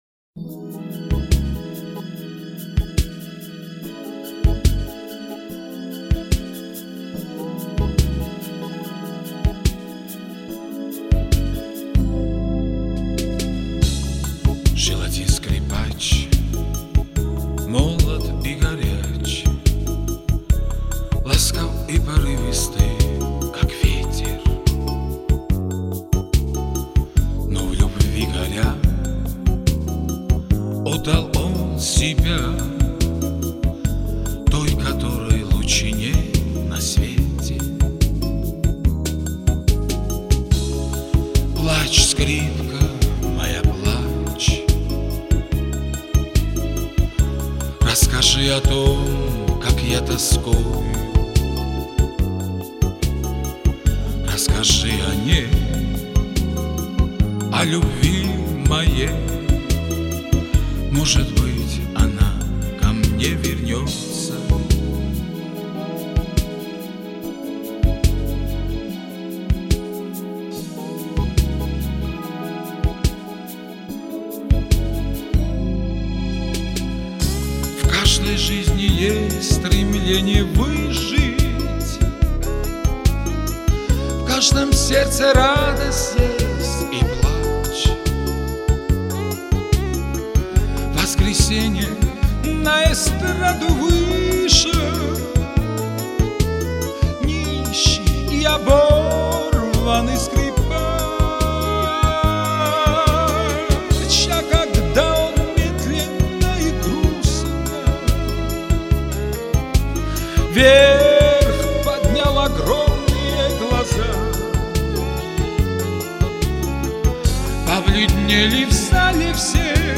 а вот как грузин поёт энту всем известную песню